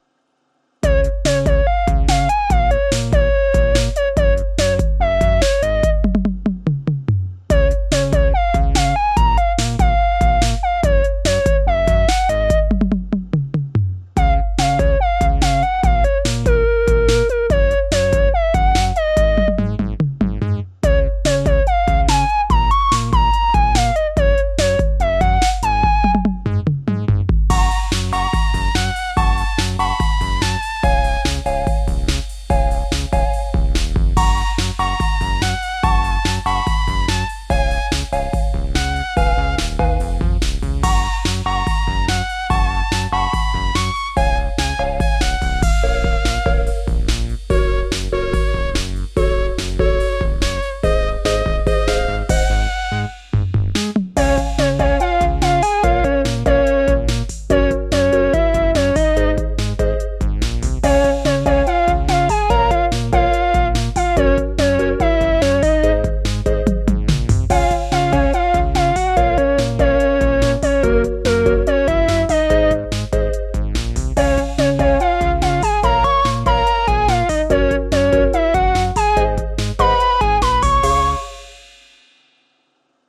meant to evoke certain cute villager games